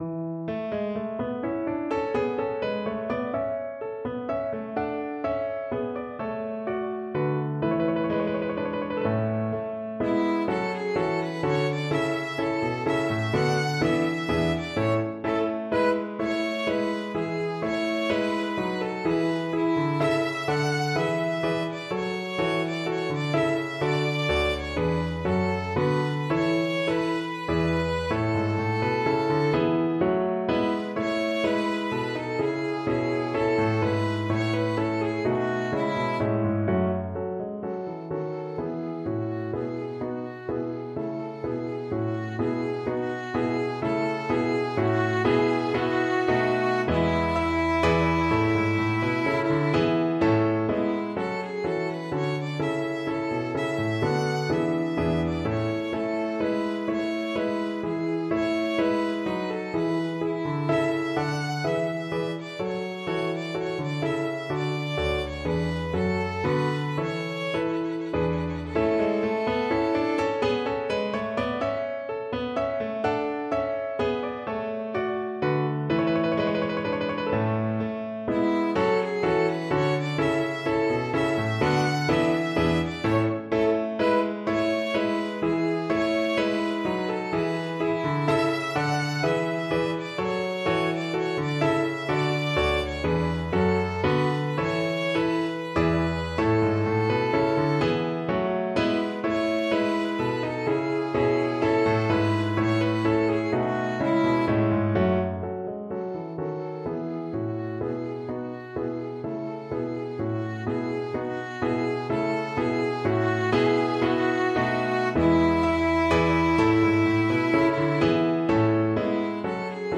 Violin
3/4 (View more 3/4 Music)
A major (Sounding Pitch) (View more A major Music for Violin )
Allegro giusto =126 (View more music marked Allegro)